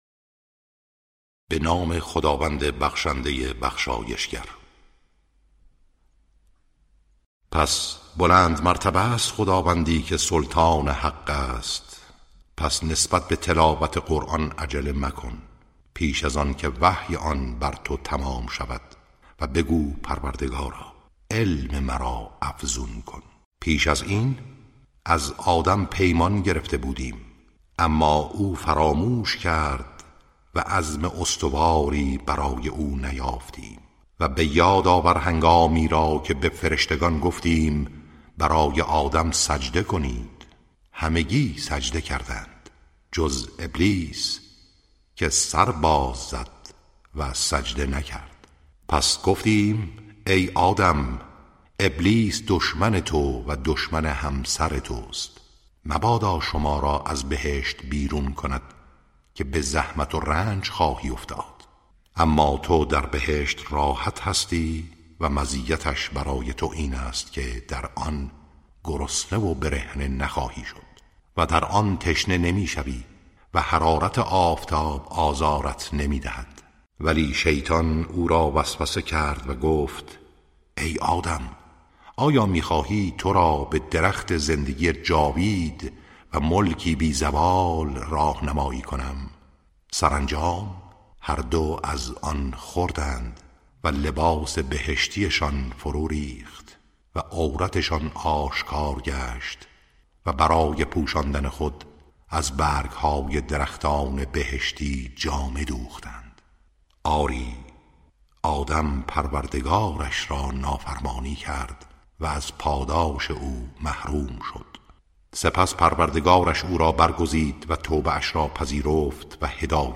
ترتیل صفحه ۳۲۰ سوره مبارکه طه(جزء شانزدهم)